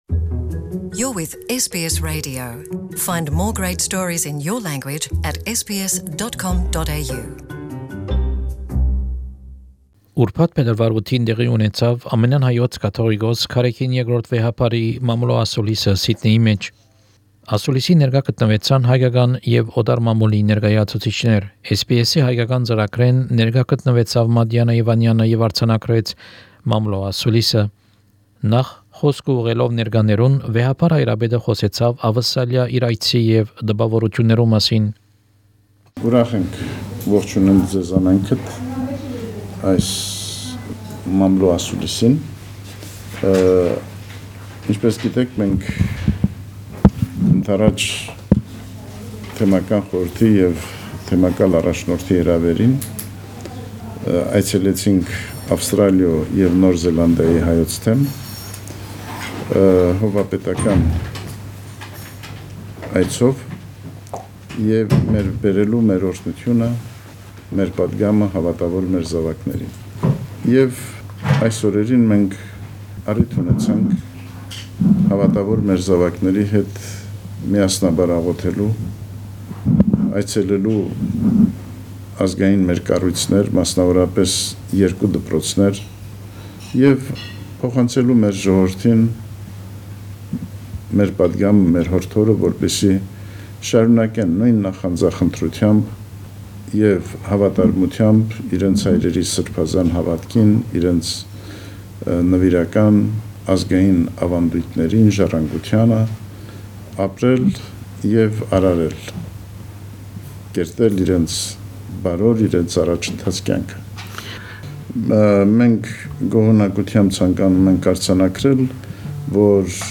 The press conference of His Holiness Karekin II Supreme Patriarch and Catholicos of All Armenians in Sydney
His Holiness Karekin II Supreme Patriarch and Catholicos of All Armenians during his Pontifical Visit to Australia gave a press conference in the Diocesan Centre in Sydney.